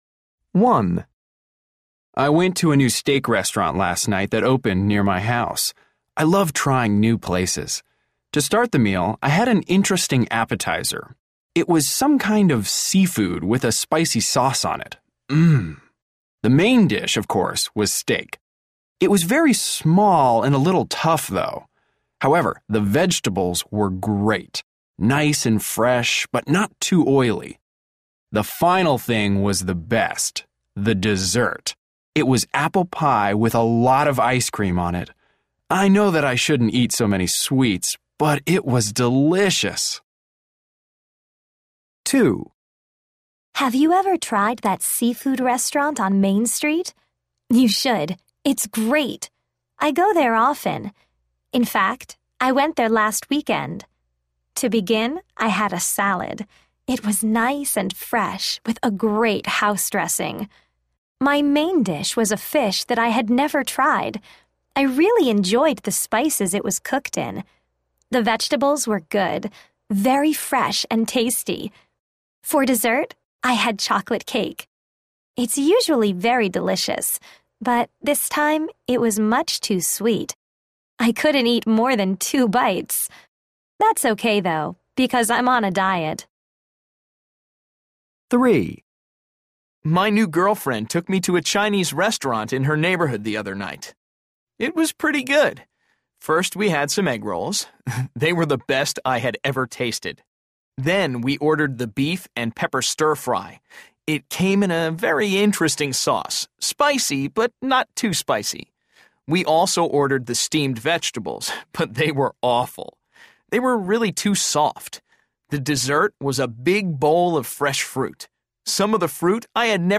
A. People are talking about meals they had at a restaurant.